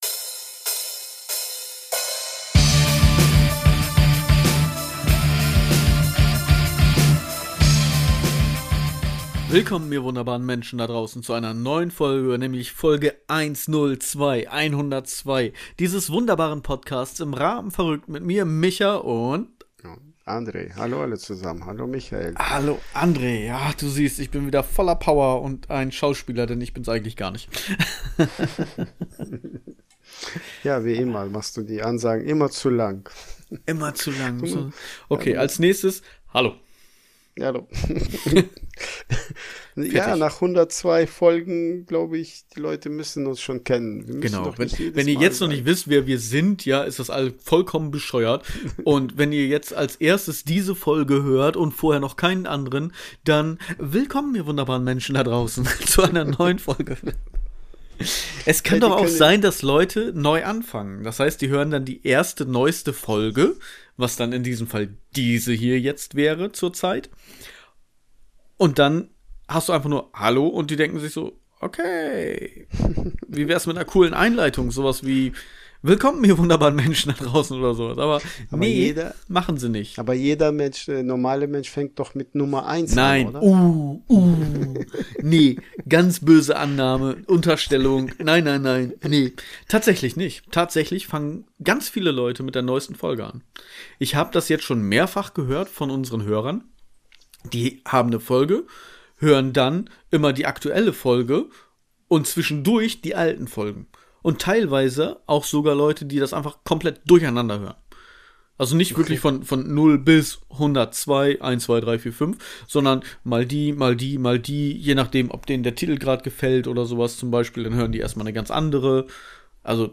Podcast aus Ostfriesland in die Welt. Wir sind 2 verrückte Typen, die sich von Früher kennen.